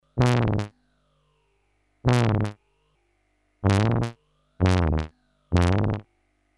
The sound was also considered warm and rich, and the synth had a couple of innovative extras (such as split and dual modes, and the clever Park function, which made it easy to find a good program location when saving sounds).
Today, the Bit-99 may not be all that exciting, but still, it's a perfectly valid analogue polysynth (although with DCOs, which don't really sound as good as real VCOs) with velocity sensitivity and an OK low pass filter.